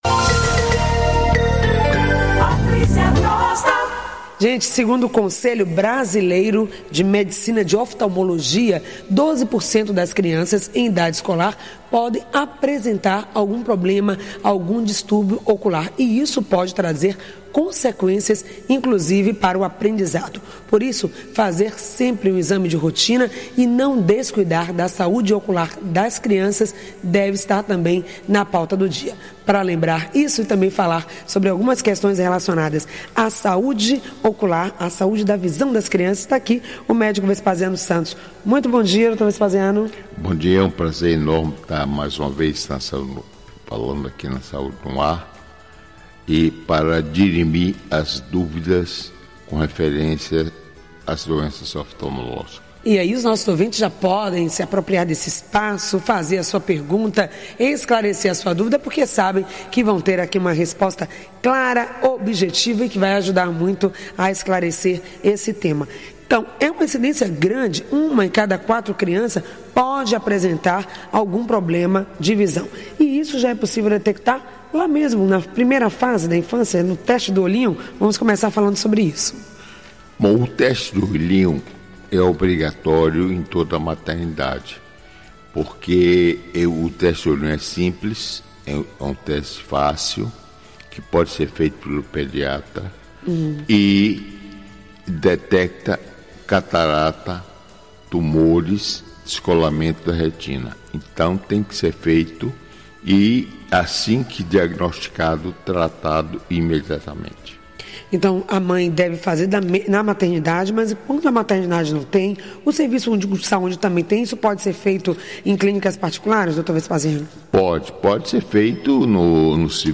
Saúde ocular das crianças - Entrevista